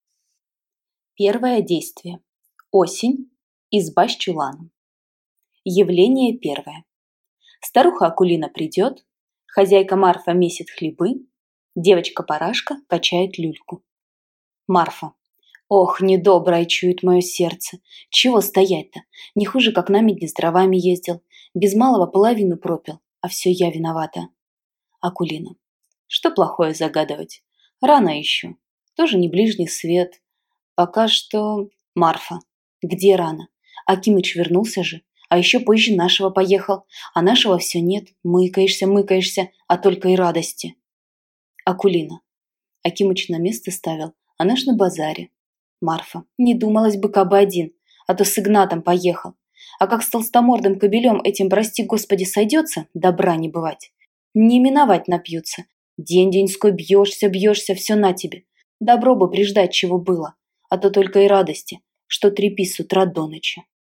Аудиокнига От ней все качества | Библиотека аудиокниг